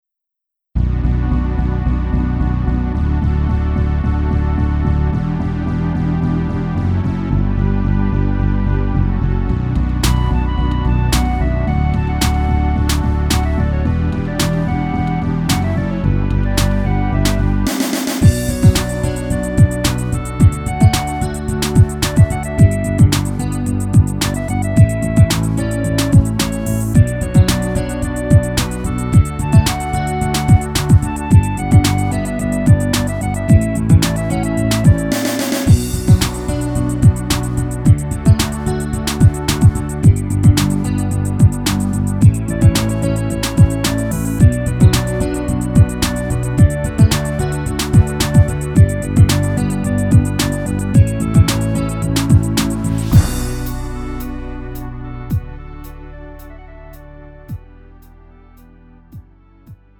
가요
Lite MR